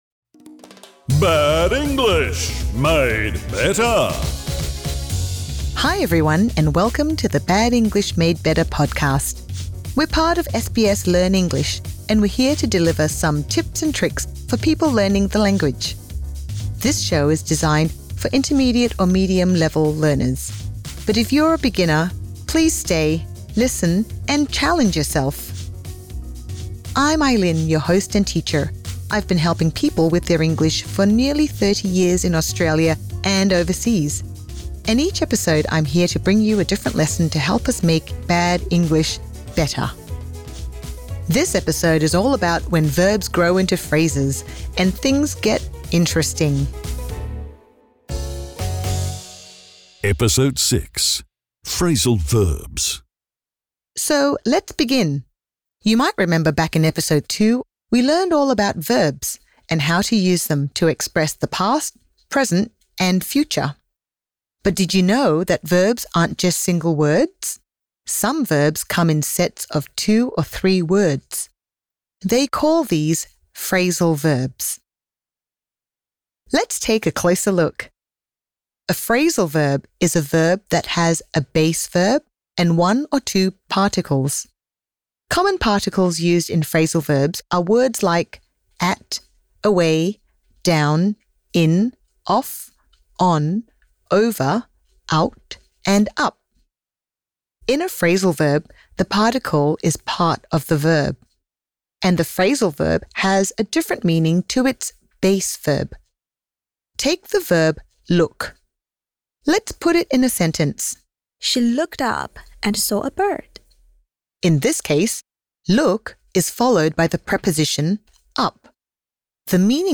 Phrasal verbs are verbs that contain more than one word. The 'Bad English Made Better' minipods are short and simple English grammar lessons.